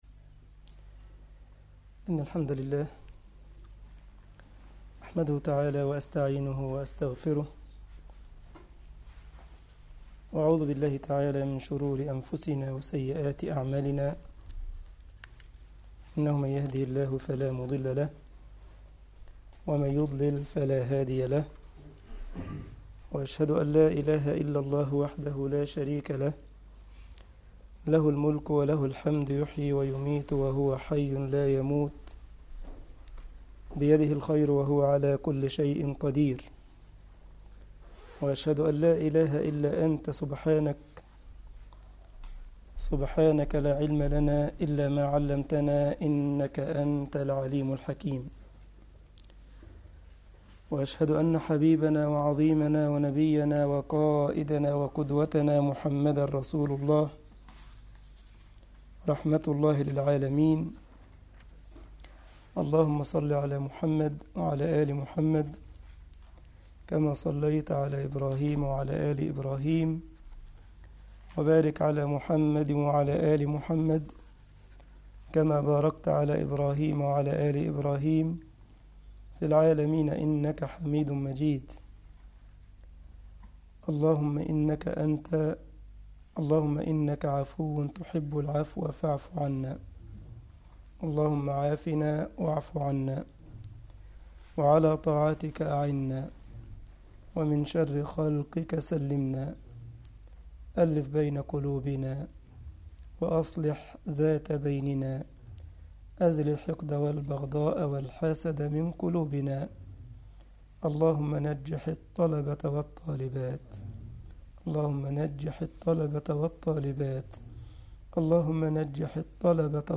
مصلى الطلبة بهومبرج ــ المانيا